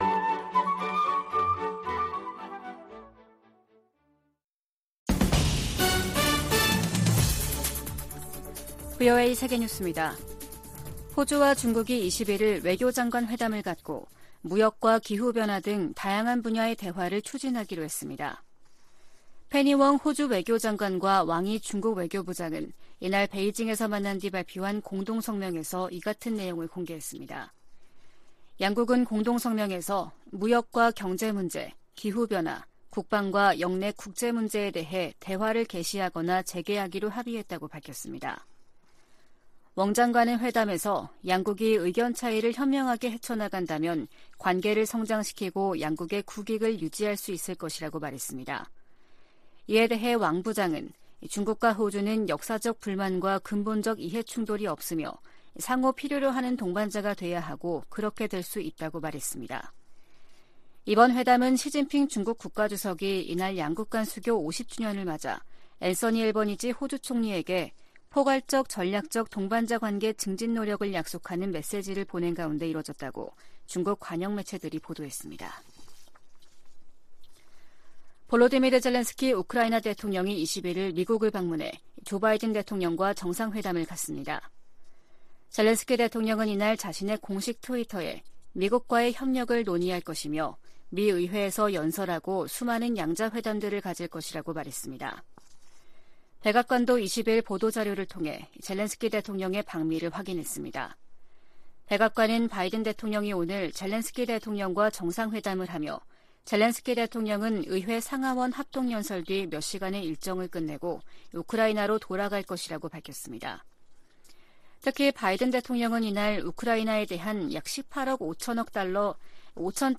VOA 한국어 아침 뉴스 프로그램 '워싱턴 뉴스 광장' 2022년 12월 22일 방송입니다. 미 국무부는 북한의 7차 핵실험이 정치적 결단만 남았다며, 이를 강행시 추가 조치를 취하겠다고 밝혔습니다. 미국 국방부가 북한의 도발 억제를 위해 역내 동맹들과 긴밀하게 협력할 것이라고 거듭 밝혔습니다.